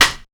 134TTCLAP2-L.wav